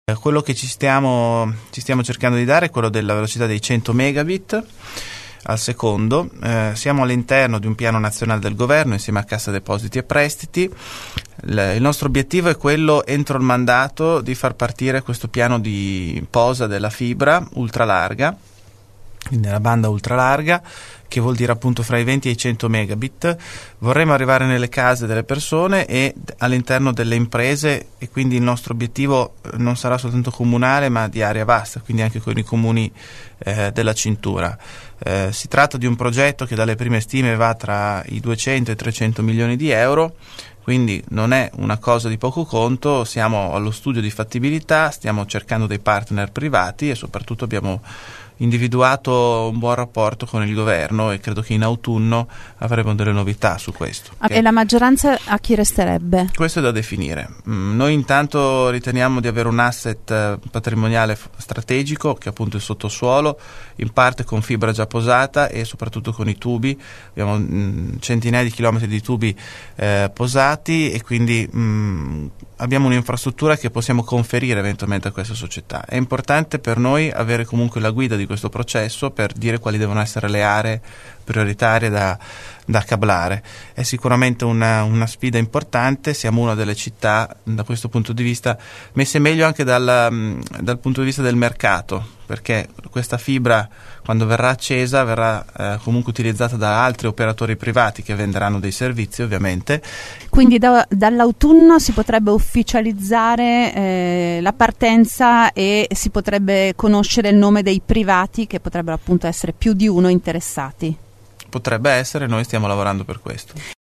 Prosegue il progetto di dotare Bologna di una banda ultra larga, anche nelle case private e nelle aziende. Già dall’autunno, ha detto l’assessore Matteo Lepore nei nostri studi, dovrebbero arrivare i nomi dei partner privati con cui l’amministrazione vuole costruire l’infrastruttura di fibra ottica nel sottosuolo.